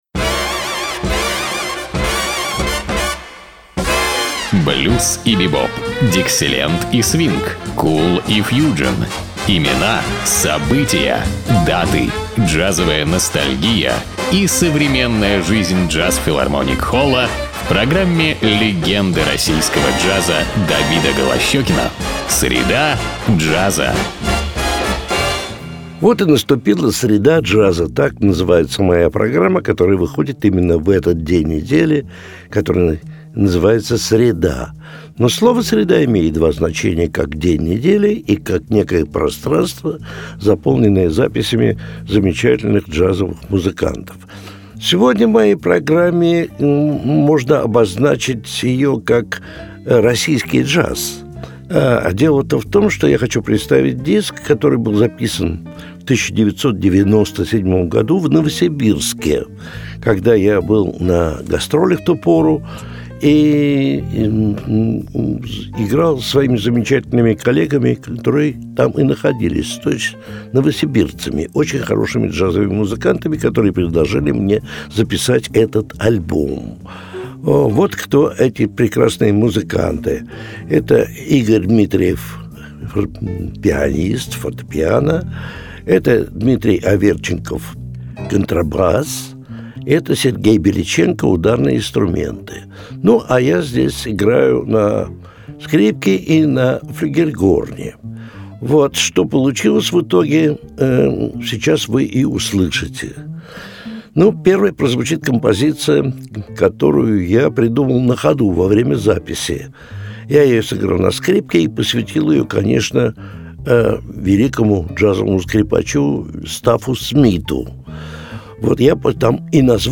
фортепьяно
контрабас
ударные
флюгельгорн, скрипка, фортепьяно